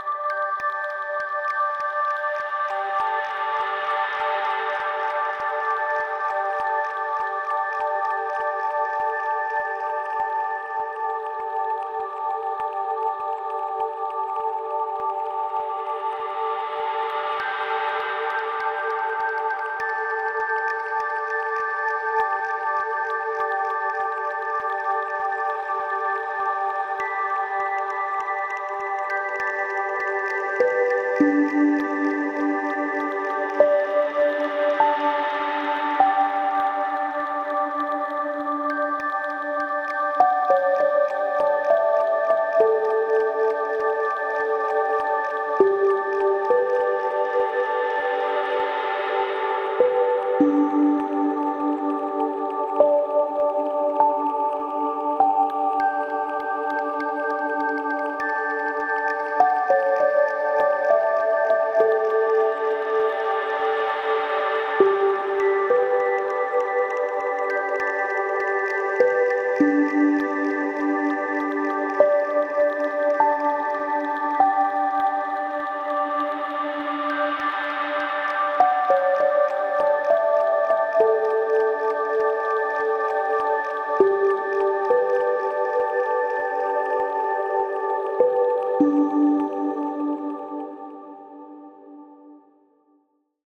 Added Ambient music pack.